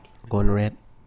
GOON-ret
If you know IPA [ gʊnret ]